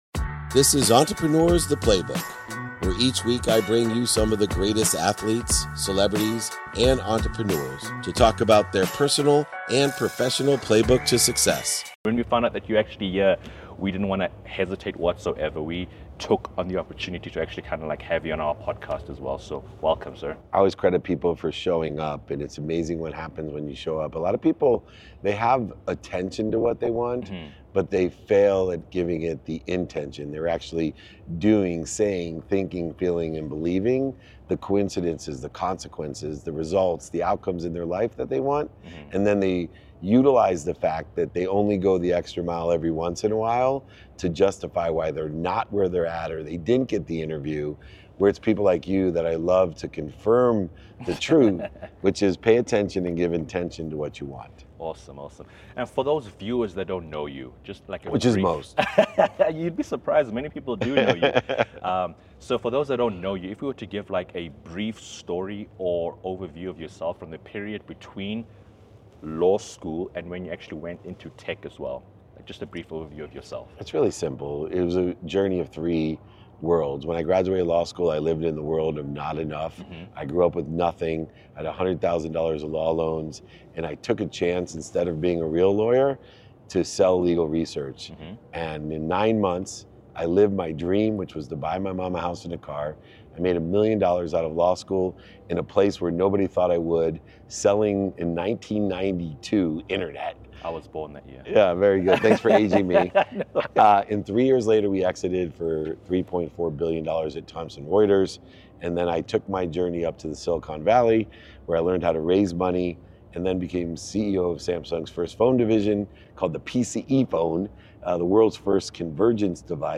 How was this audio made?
at Solar Con.